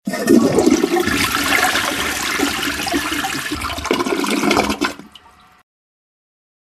Звуки канализации
На этой странице собраны разнообразные звуки канализации: от тихого бульканья воды до резонанса в трубах.
Звук воды при сливе в туалете вариант 2